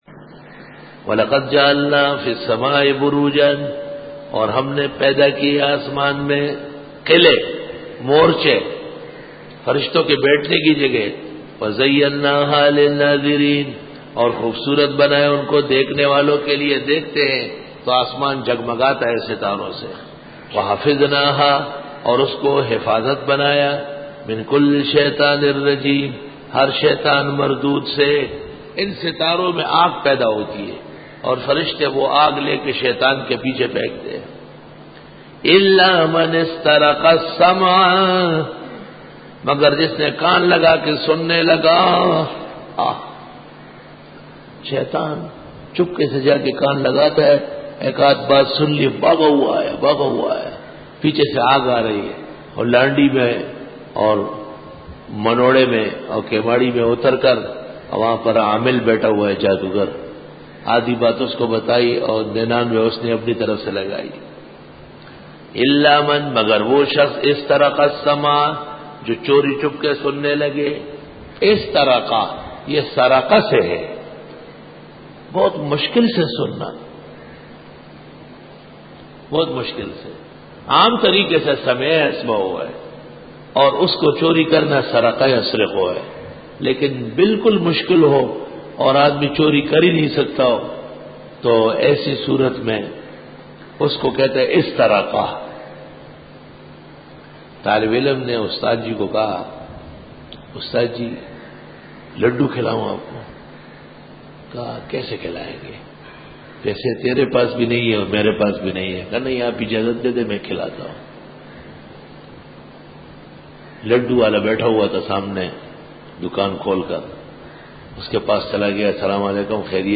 سورۃ الحجررکوع-02 Bayan